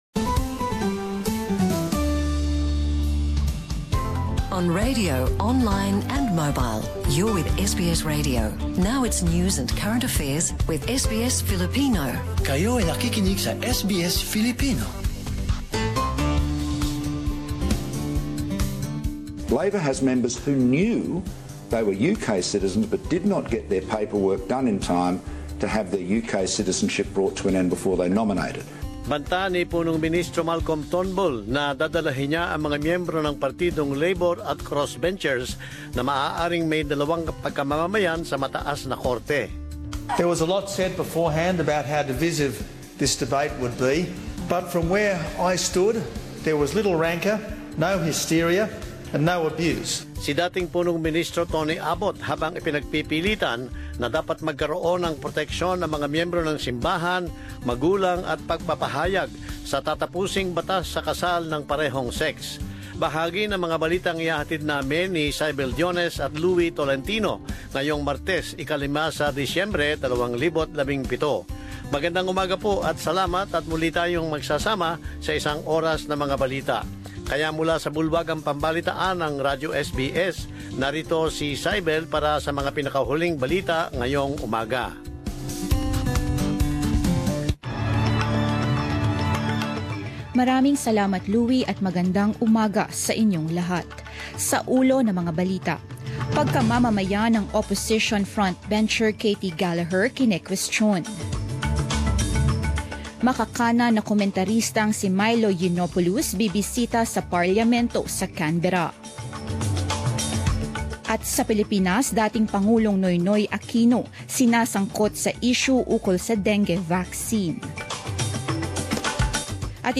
Balita ng alas 10 ng umaga sa Wikang Filipino
Ika-sampu ng umagang balita sa Wikang Filipino 05 Disyembre 2017, Martes